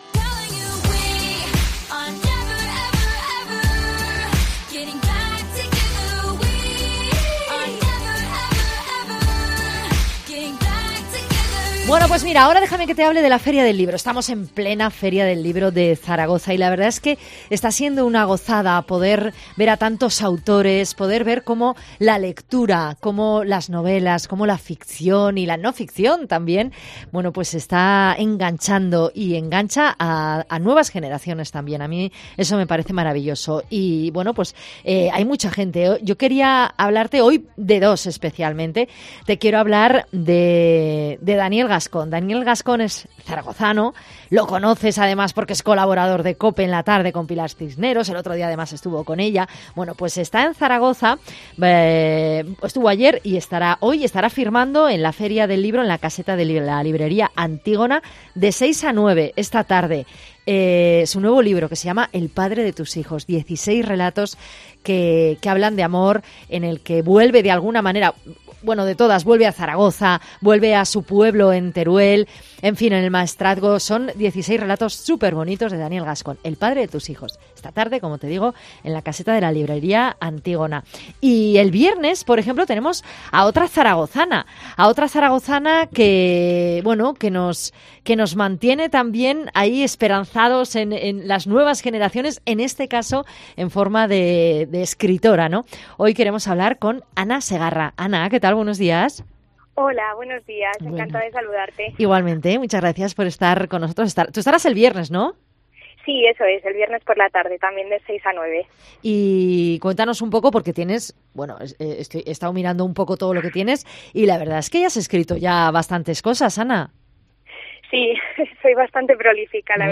Entrevista
Feria del libro de Zaragoza